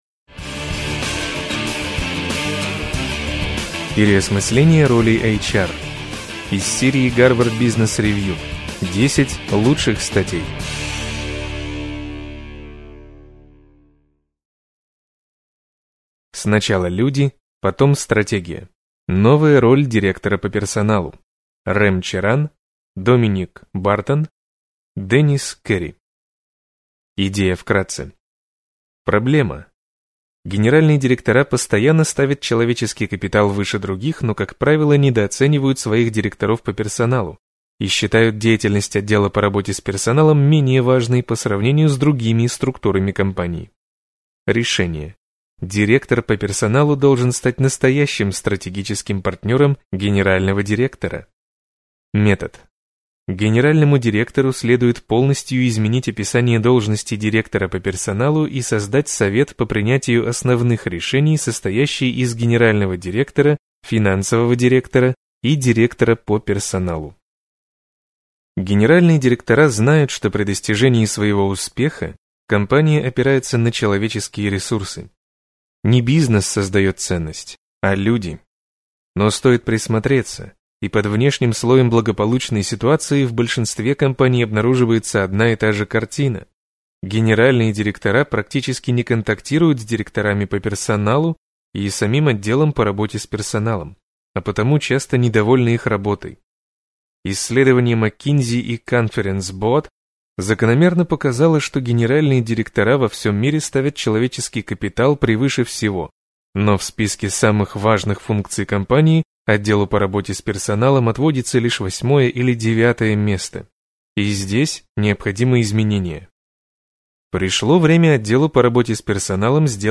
Аудиокнига Переосмысление роли HR | Библиотека аудиокниг